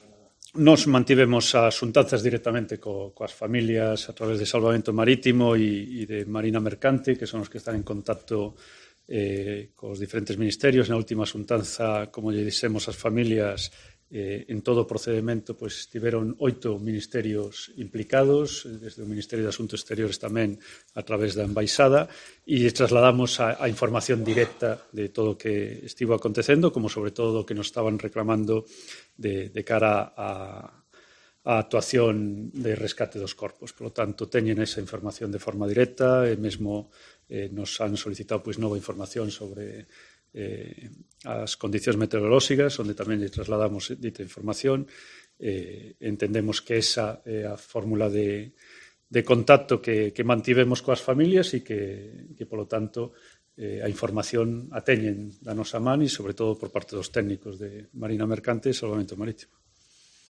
Respuesta del delegado del Gobierno en Galicia a pedir una videoconferencia con Canadá por el Villa de Pitanxo